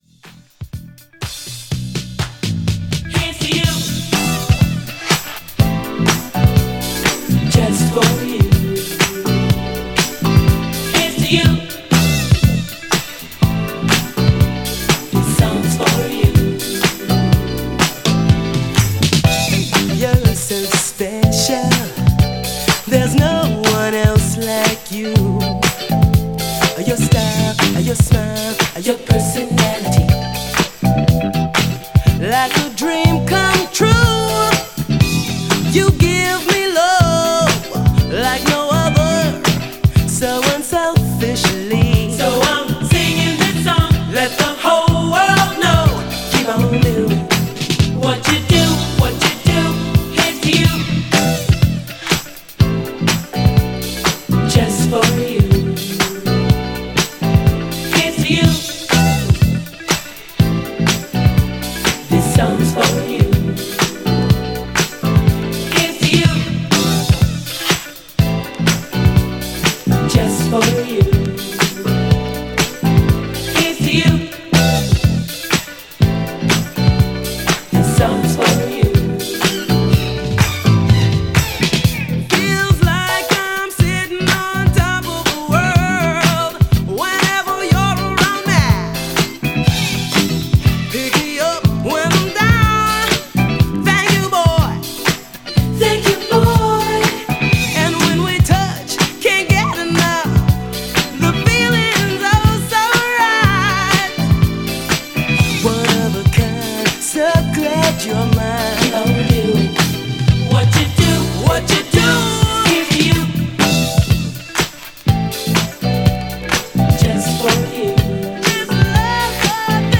DANCE
細かな擦り＆チリチリあります。